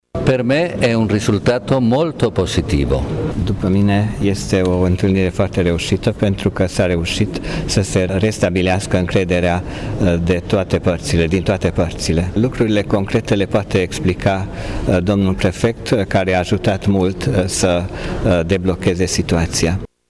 Aceasta este concluzia exprimată, azi, la Tg.Mureș, de către Nunţiul Apostolic al Sfântului Scaun în România, Miguel Maury Buendia. După o întrevedere de aproximativ două ore cu autorităţile locale, reprezentaţi ai Bisericii Romano-Catolice şi politicieni, ambasadorul Vaticanului a declarat: